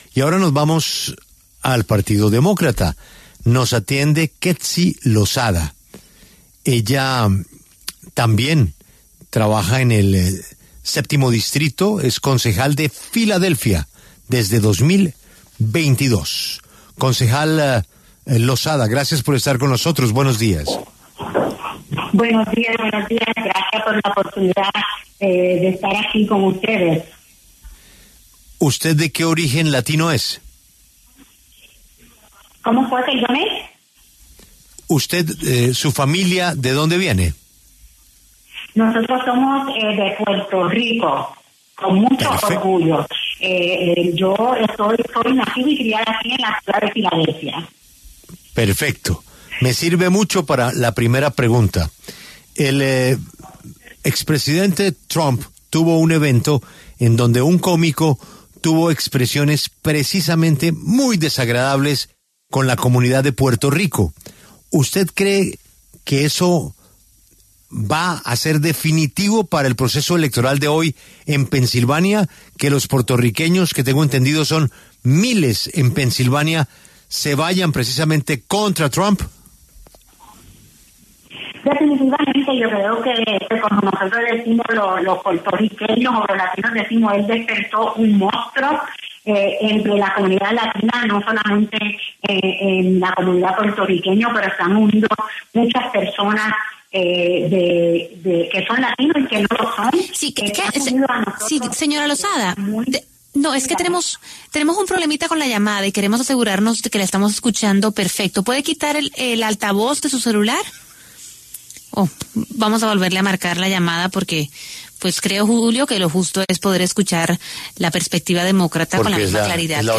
En diálogo con La W, la política Quetcy Lozada, quien representa al séptimo distrito como concejal en el Ayuntamiento de Philadelphia en Estados Unidos desde 2022, se pronunció acerca de las elecciones que se llevan a cabo en su país este 5 de noviembre.